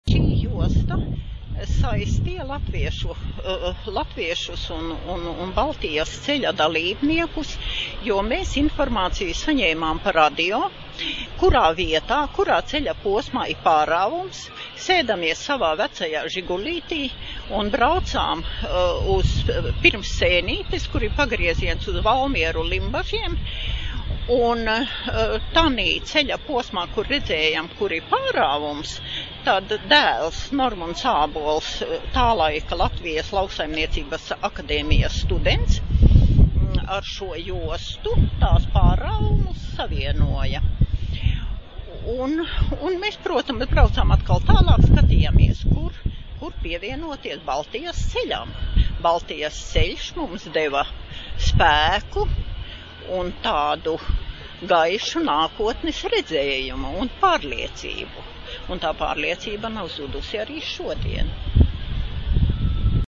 Atmiņu stāsts ierakstīts Eiropas digitālās bibliotēkas "Europeana" un Latvijas Nacionālās bibliotēkas organizētajās Baltijas ceļa atceres dienās, kas notika 2013. gada 23. un 24. augustā Rīgā, Esplanādē.